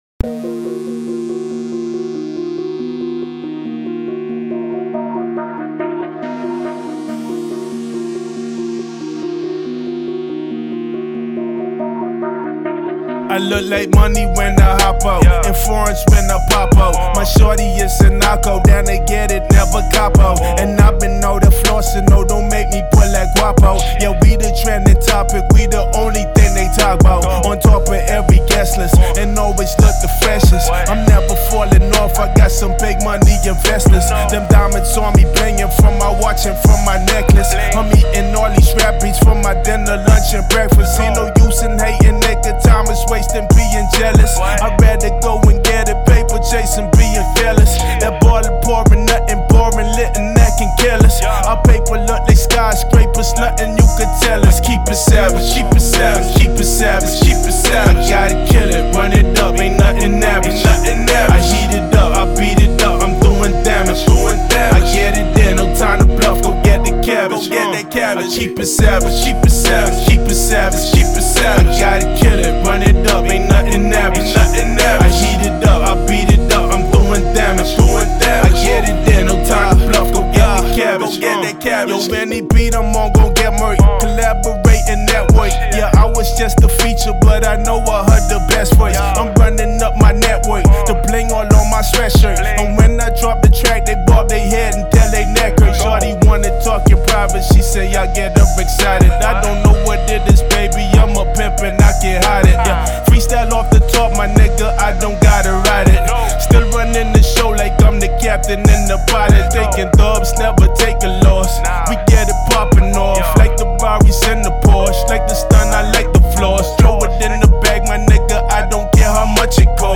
This is a mp3 acapella file and does not include stems